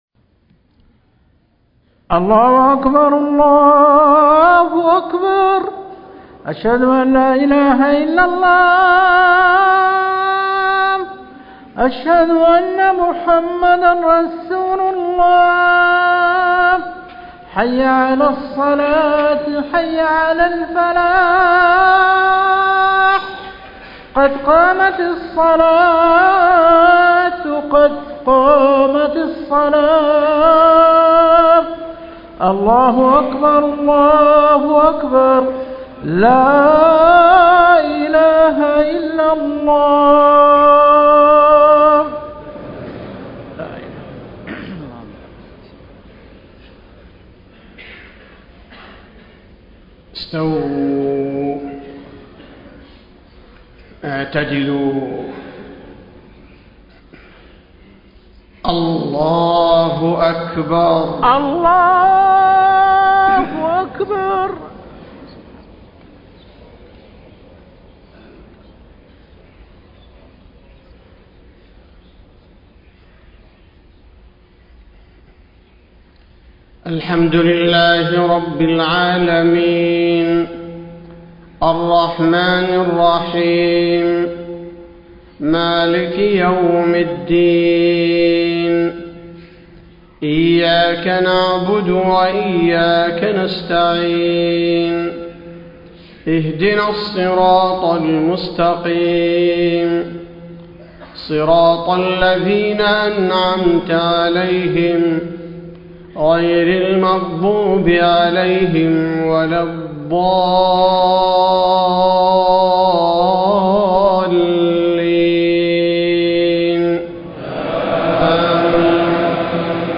صلاة الفجر 1 - 4 - 1434هـ آخر سورتي الفرقان و لقمان > 1434 🕌 > الفروض - تلاوات الحرمين